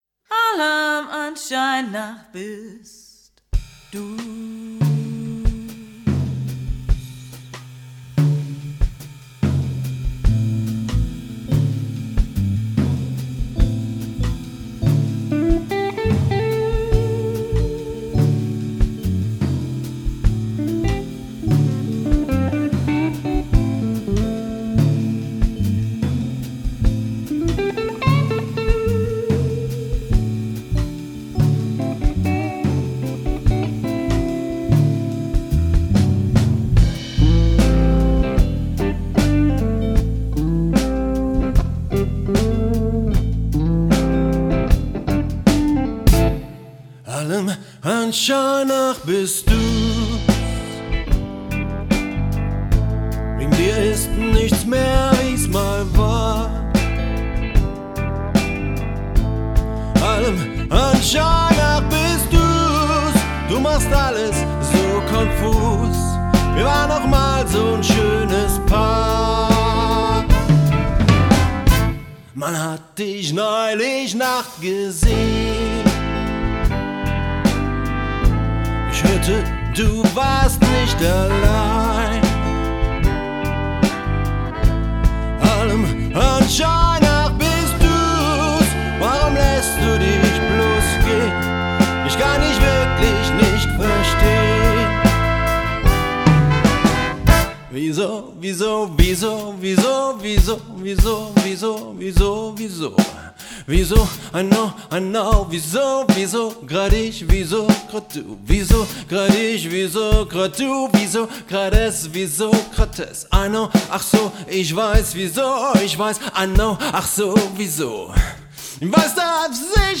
· Genre (Stil): Soul